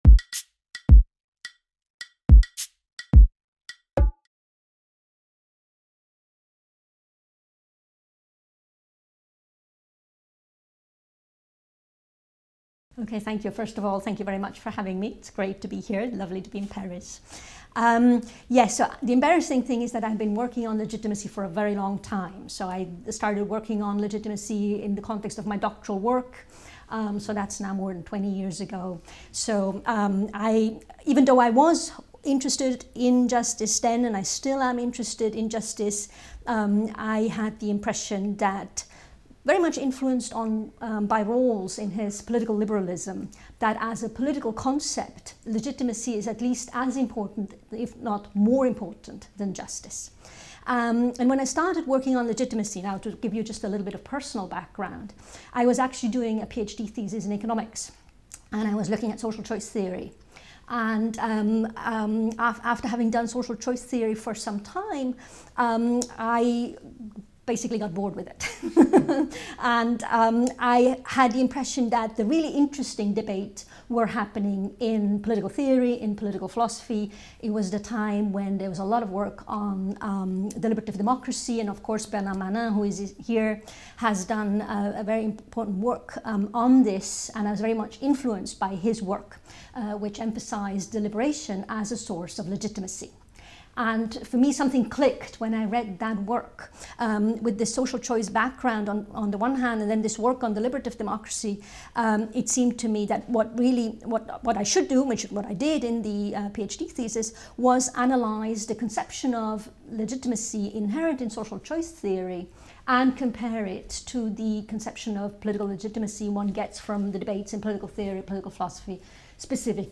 The Grounds of Political Legitimacy : Interview